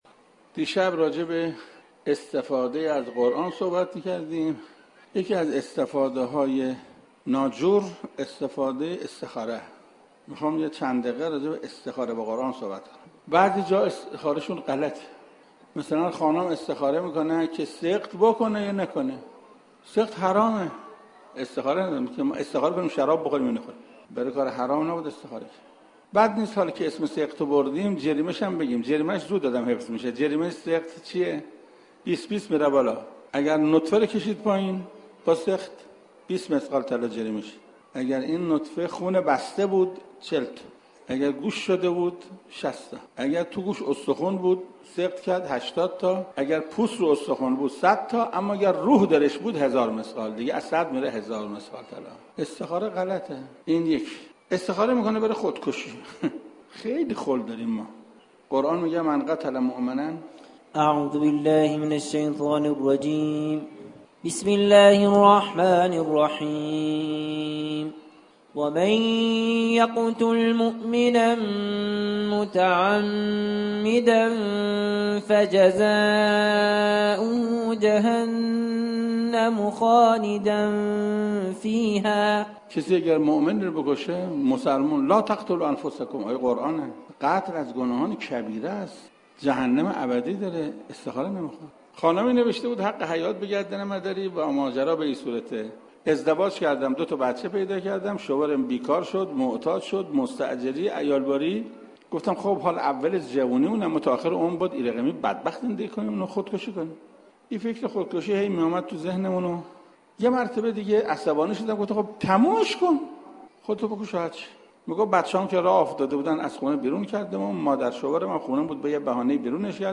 صوت سخنرانی حجت الاسلام و المسلمین قرائتی درباره استخاره های غلط منتشر می شود.